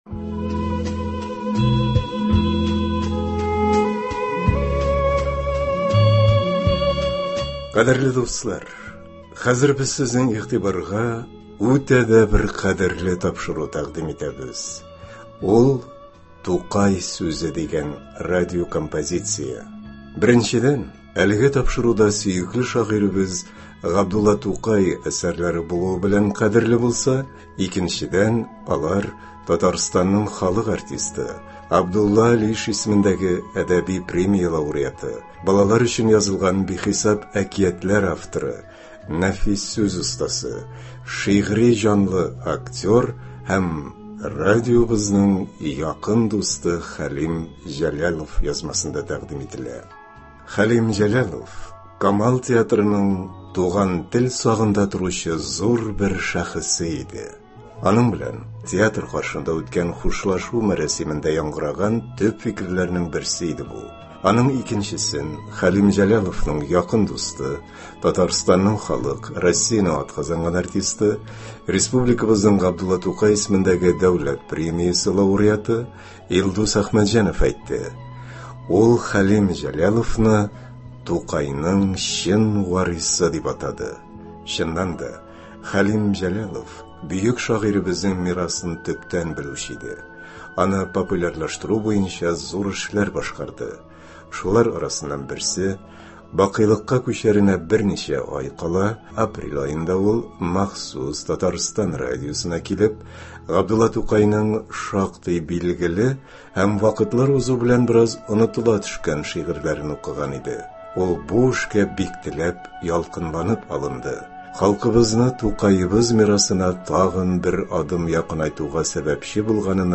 “Тукай сүзе”. Радиокомпозиция.
Шулар арасыннан берсе – бакыйлыкка күчәренә берничә ай кала, апрель аенда, ул махсус Татарстан радиосына килеп, Г.Тукайның шактый билгеле һәм вакытлар узу белән бераз онытыла төшкән шигырьләрен укыган иде.